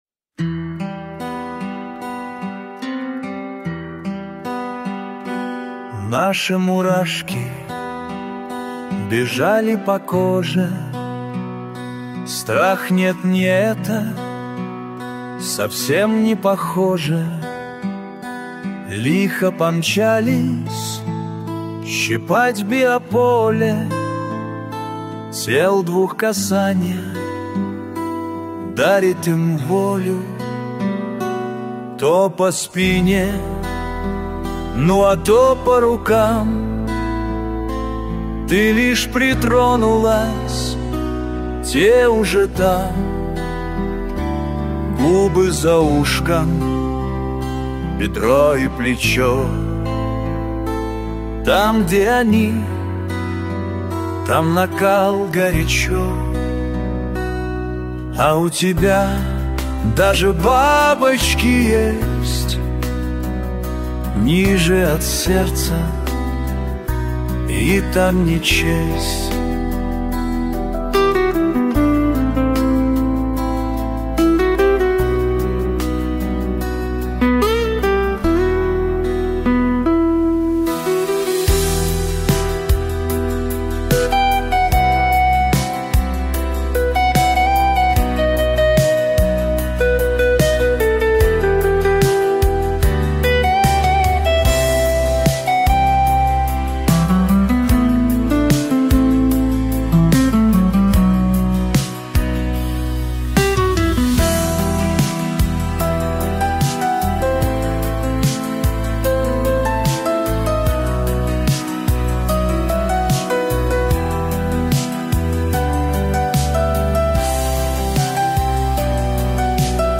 Текст - автора, виконання пісні - ШІ
ТИП: Пісня
СТИЛЬОВІ ЖАНРИ: Романтичний